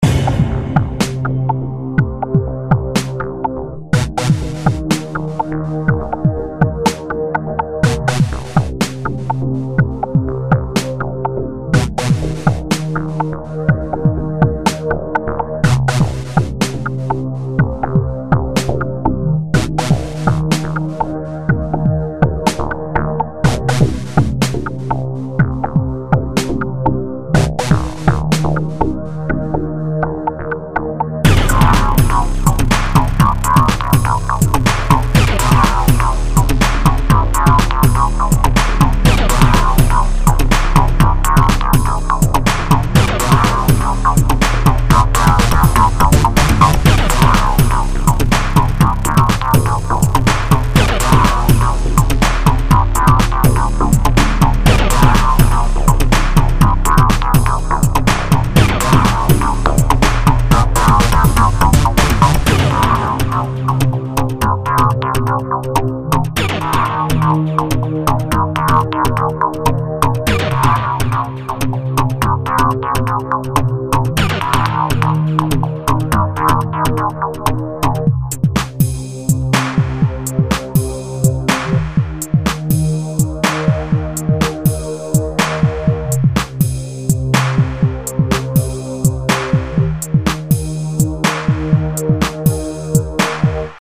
I wanted to capture the slow jungle type "before a big fight starts" feeling if you know what I mean.
Also sorry for the long intro.
Like a space sim battle scene or a space based FPS ambient/battle sequence.